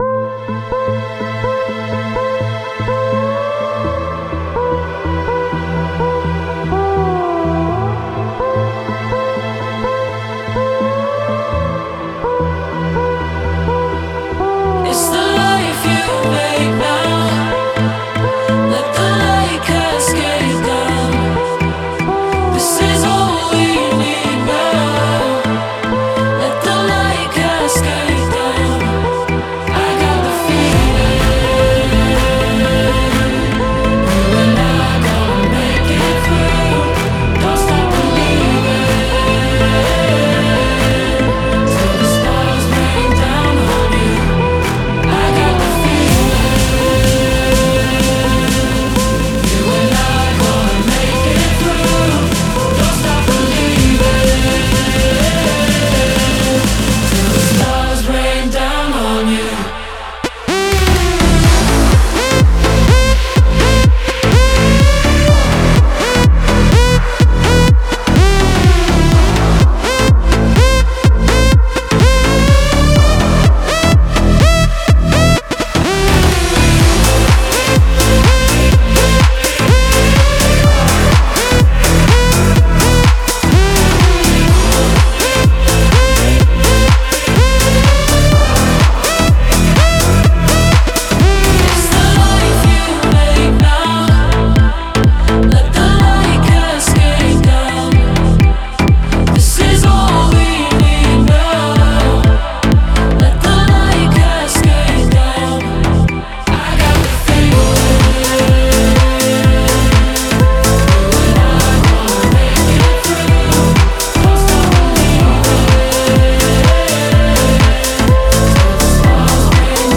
скачать Club House